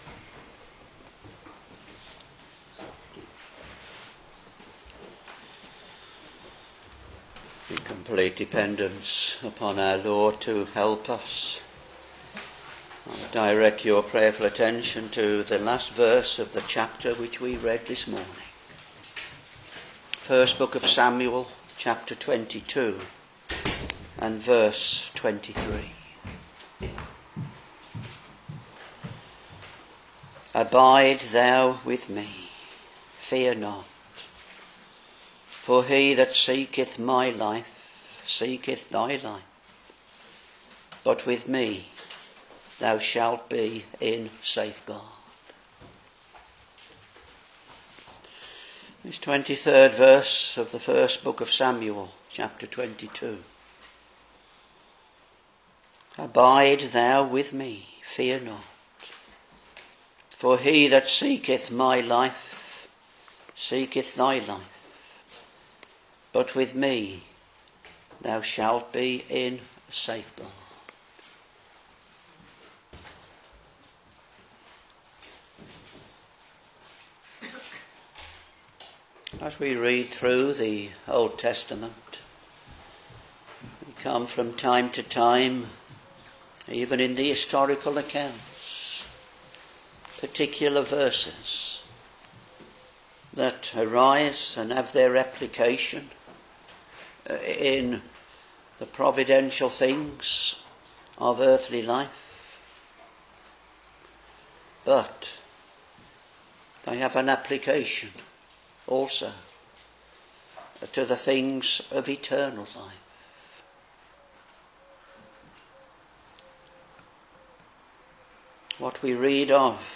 Back to Sermons 1 Samuel Ch.22 v.23 Abide thou with me, fear not: for he that seeketh my life seeketh thy life: but with me thou shalt be in safeguard.